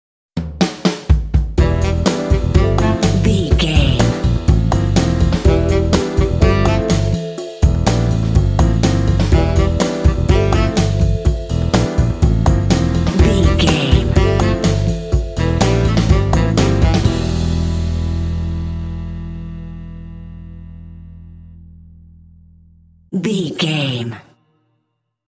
Phrygian
funky
bouncy
groovy
saxophone
piano
bass guitar
drums
jazz